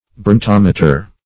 Search Result for " brontometer" : The Collaborative International Dictionary of English v.0.48: Brontometer \Bron*tom"e*ter\, n. [Gr.